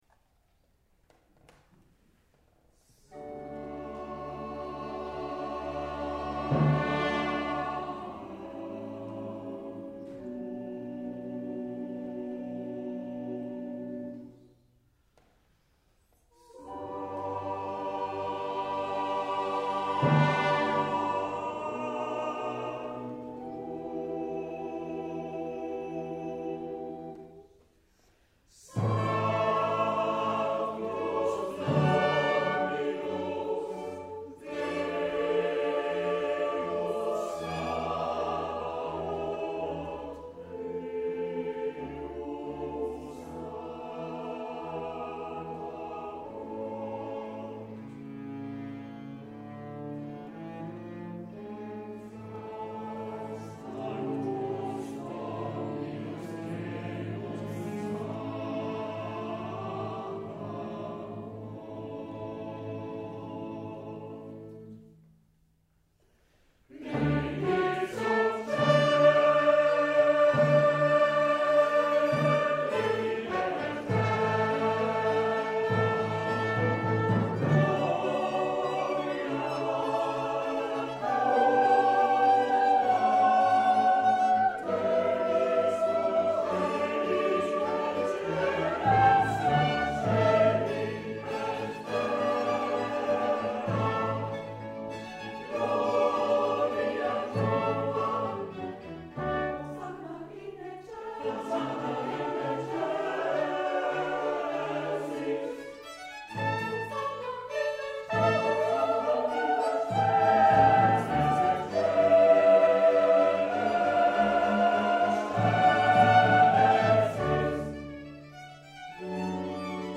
Concert de l’Avent (Sanctus + Benedictus)